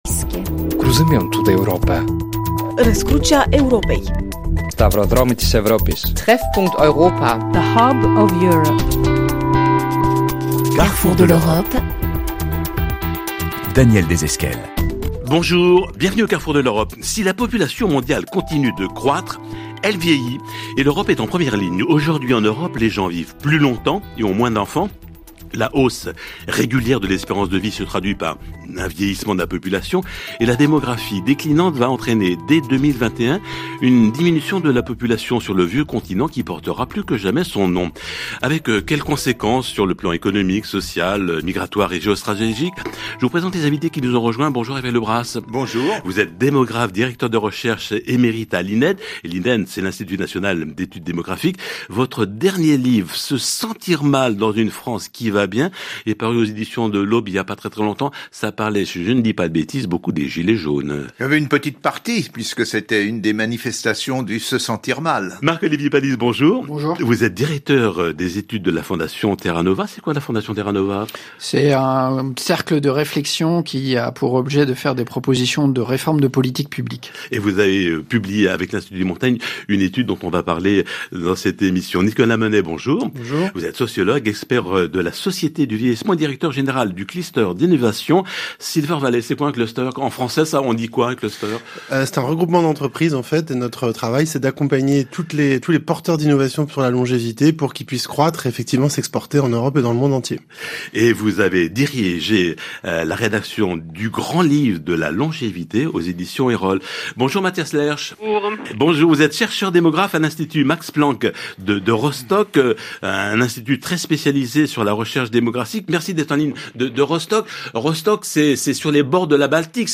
Écoutez le reportage sur la diminution des naissances en Espagne pour permettre à vos étudiants de rédiger une synthèse.